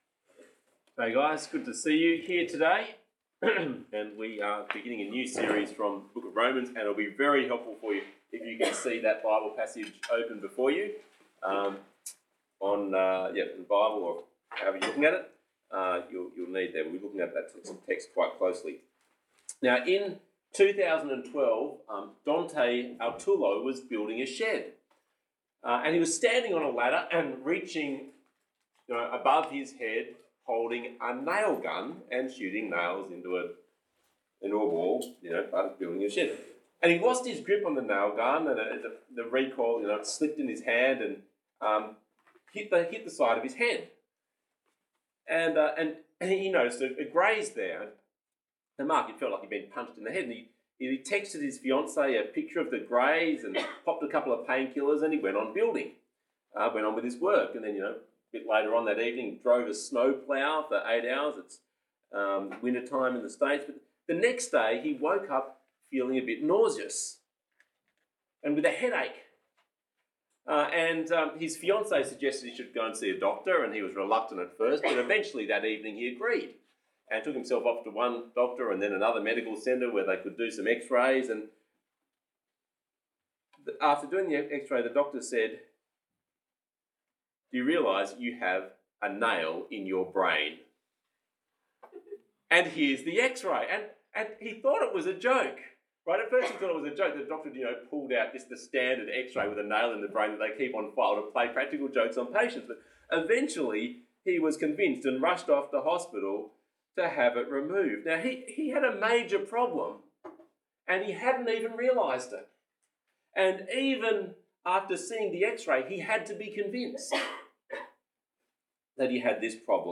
Talk Type: Bible Talk